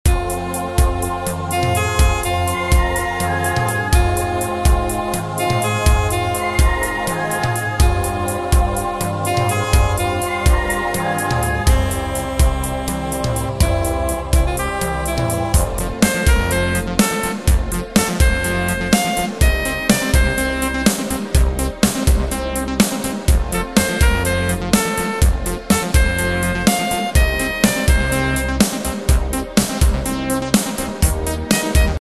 Tempo: 124 BPM.
MP3 with melody DEMO 30s (0.5 MB)zdarma